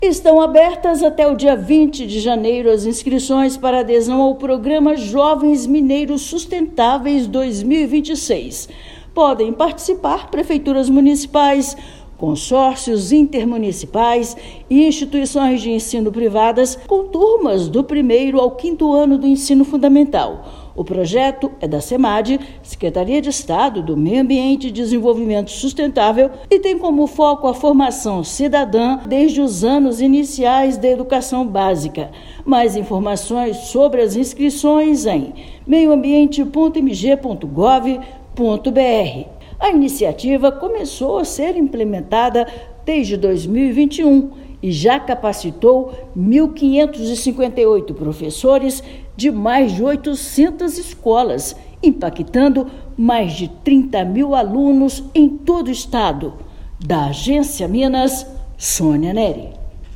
Adesões ao programa podem ser feitas até 20/1. Ouça matéria de rádio.